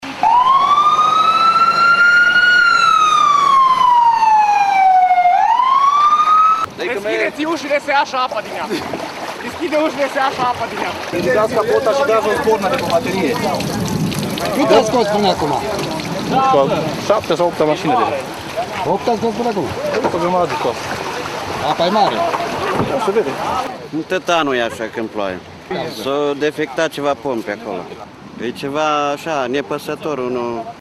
2.voxuri-arad-furtuna.mp3